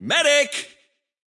Medic_intense_1.wav